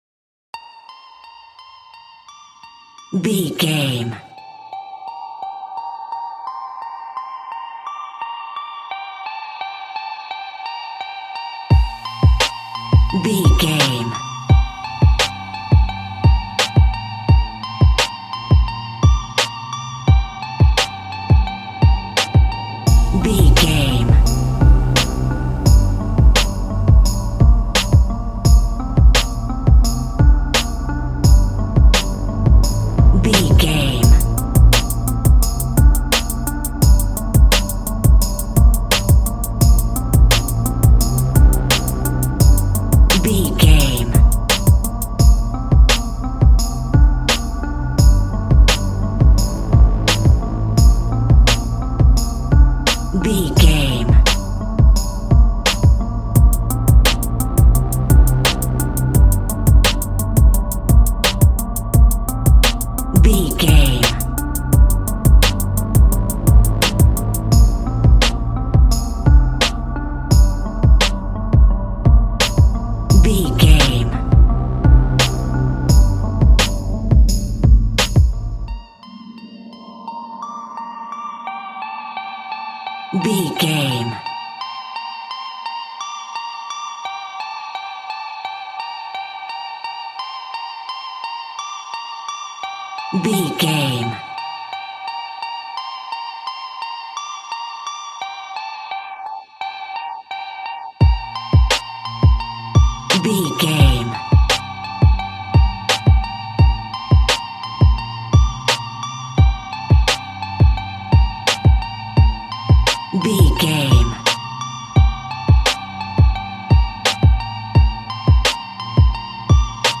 royalty free music
Ionian/Major
B♭
hip hop instrumentals
chilled
laid back
groove
hip hop drums
hip hop synths
piano
hip hop pads